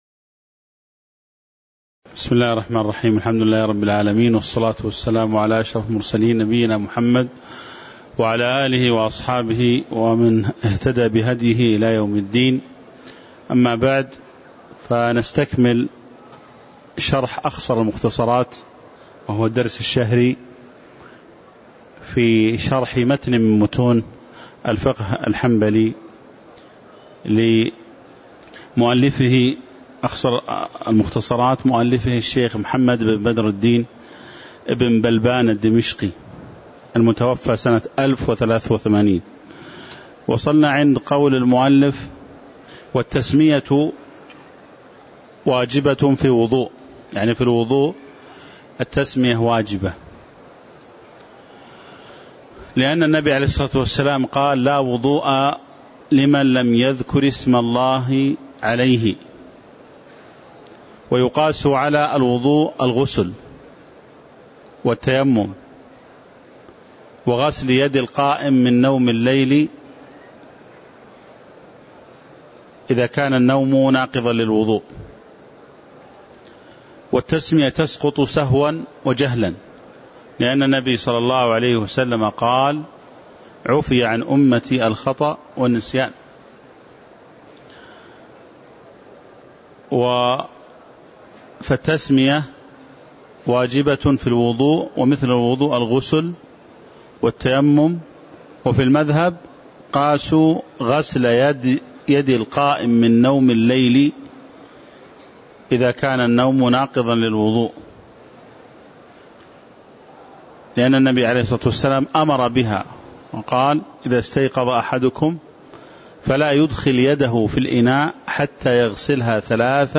تاريخ النشر ١٤ صفر ١٤٣٩ هـ المكان: المسجد النبوي الشيخ